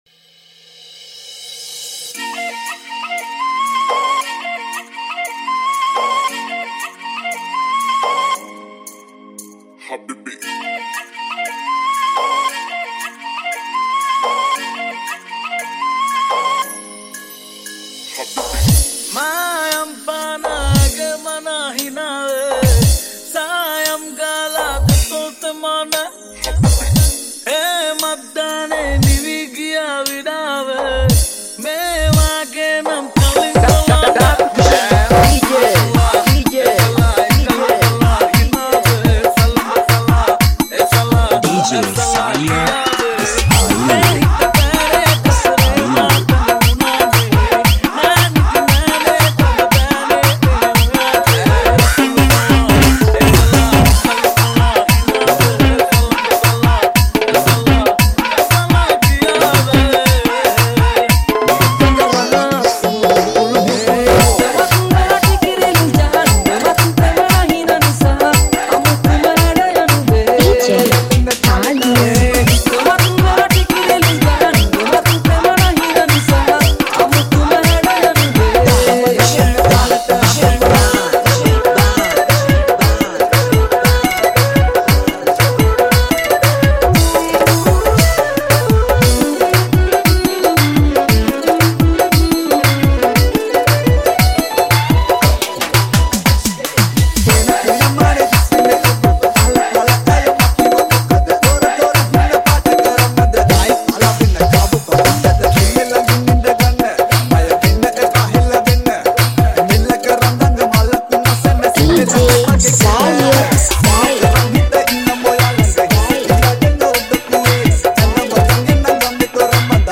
High quality Sri Lankan remix MP3 (10).